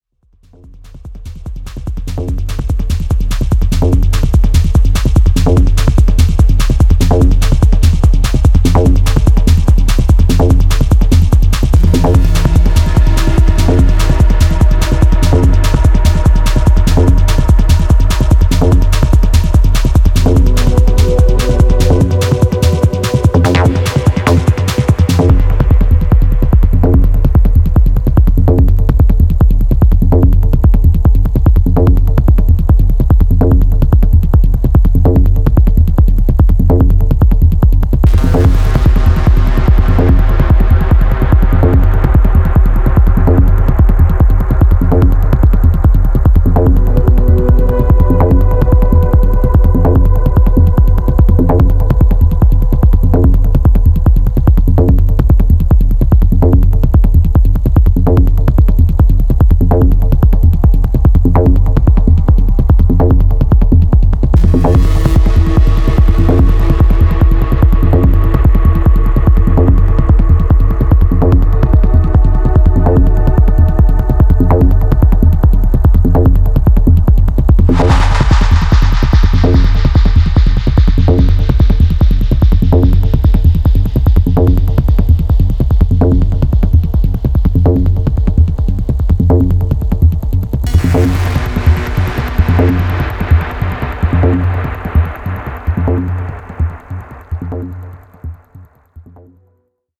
smooth and warm
Techno